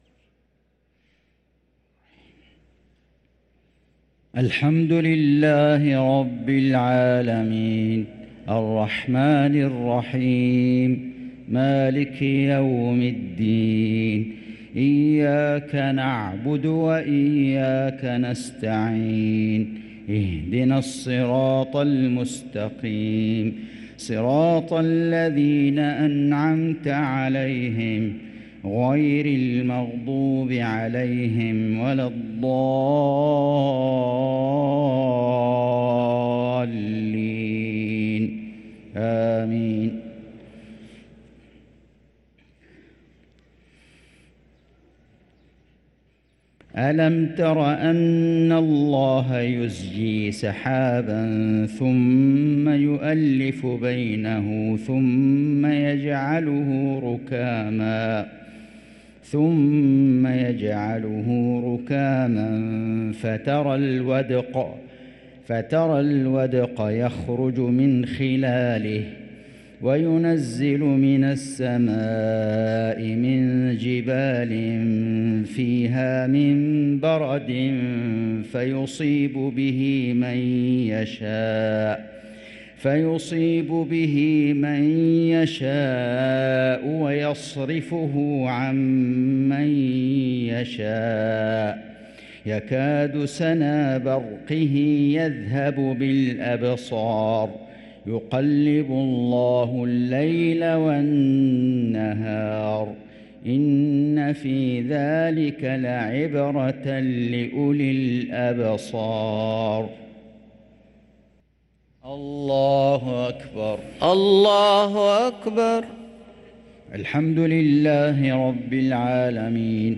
صلاة العشاء للقارئ فيصل غزاوي 12 ربيع الأول 1445 هـ
تِلَاوَات الْحَرَمَيْن .